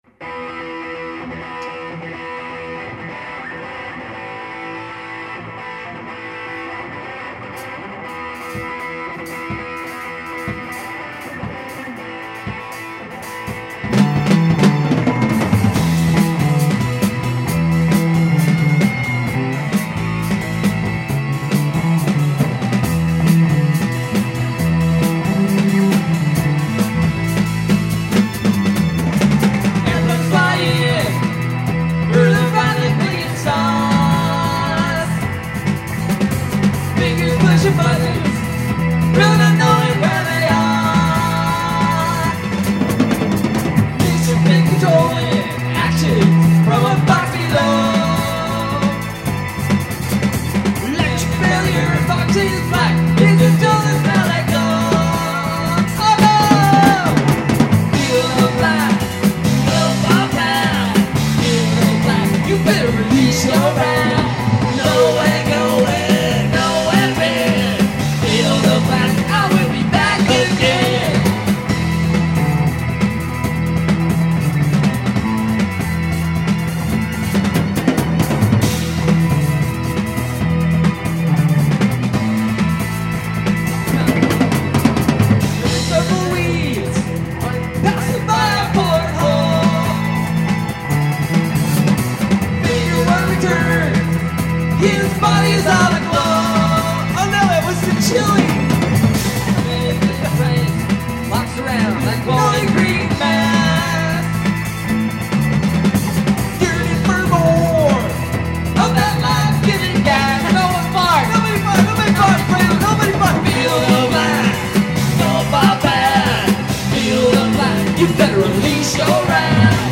1998 Practices FoB
The famous extended jam